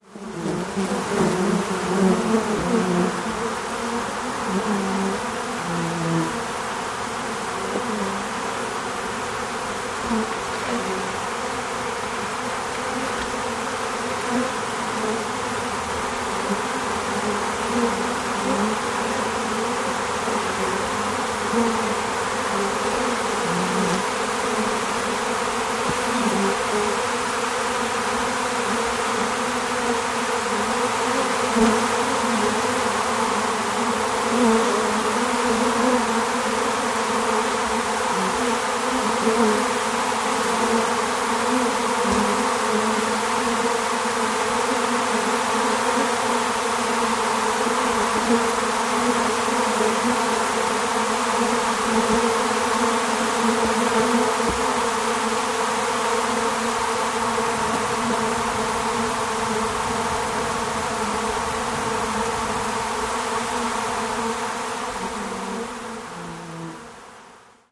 Beehive, Noisy, Ambient